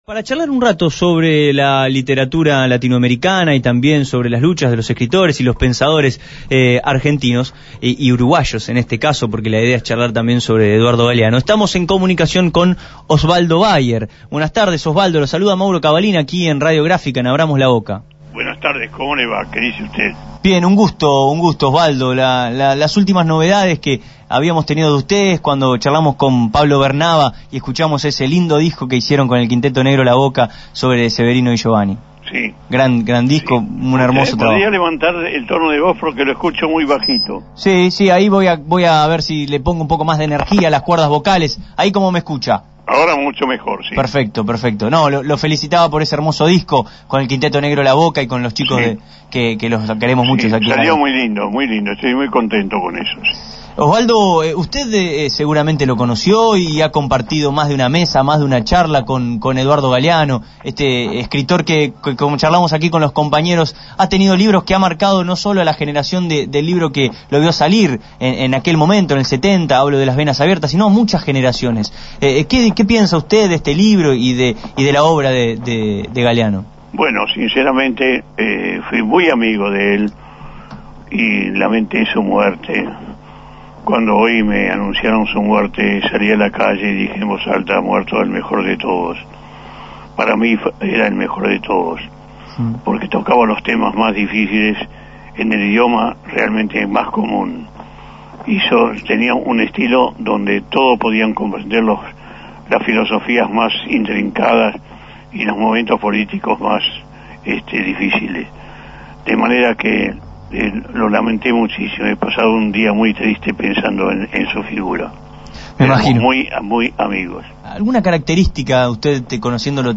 Tras conocerse la noticia del fallecimiento del pensador y escritor de la Patria Grande, Eduardo Galeano, Radio Gráfica dialogó con el escritor e historiador Osvaldo Bayer, colega y amigo del escritor uruguayo: «Ha muerto el mejor de todos».